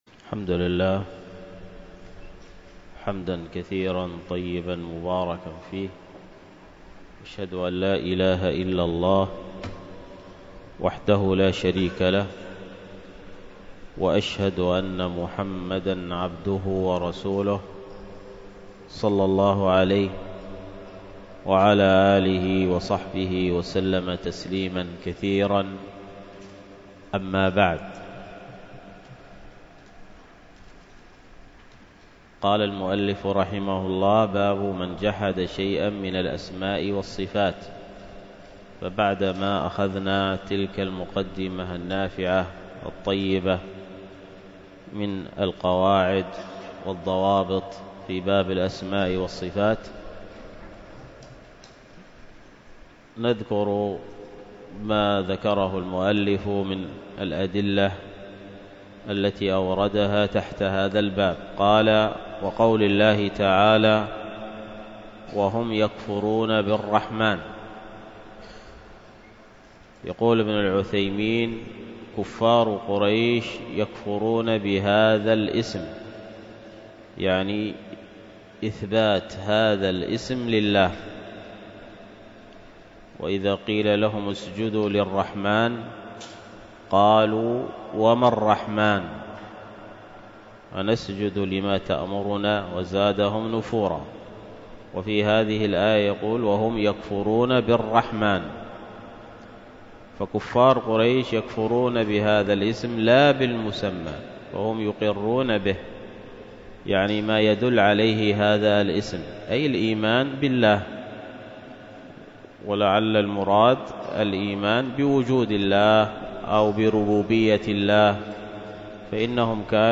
الدرس في التعليق على التقسيم والتقعيد للقول المفيد 133، ألقاها الشيخ